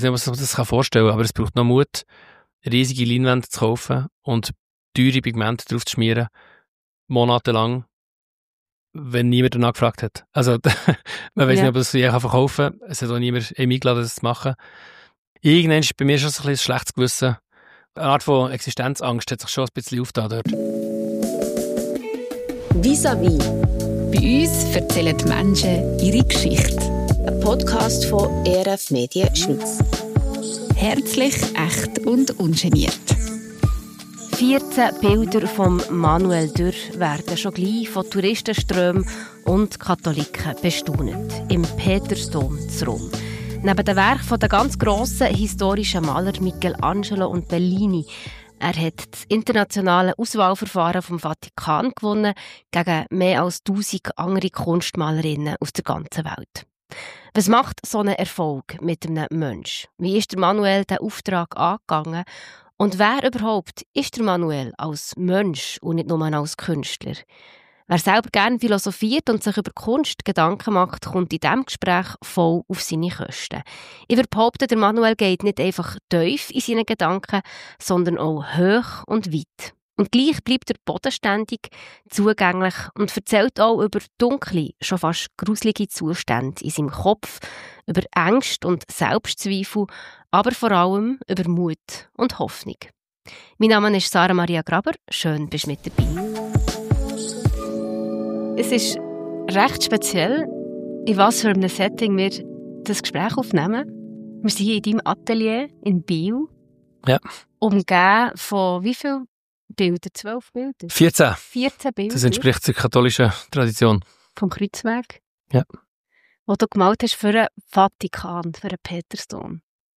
Wer sich Gedanken über Kunst und Philosophie macht, kommt in diesem Gespräch auf seine Kosten.